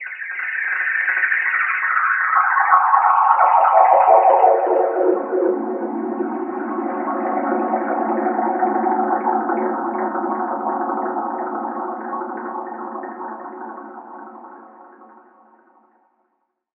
Watery Drain.wav